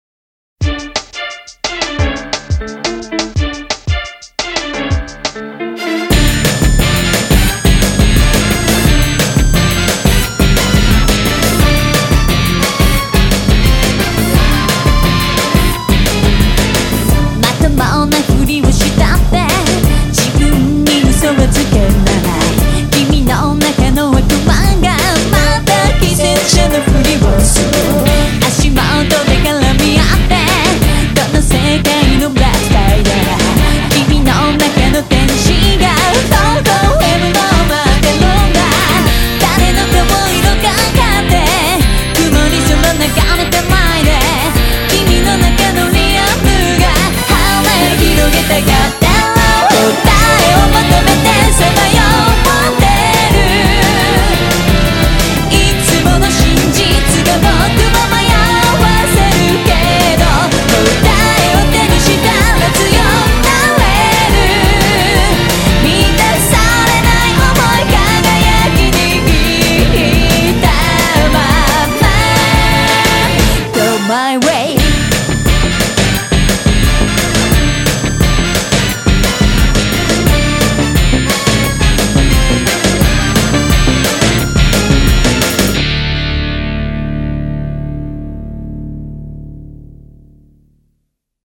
BPM175
Audio QualityPerfect (High Quality)
A sultry brass-rock song